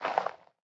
Minecraft Version Minecraft Version latest Latest Release | Latest Snapshot latest / assets / minecraft / sounds / block / composter / fill3.ogg Compare With Compare With Latest Release | Latest Snapshot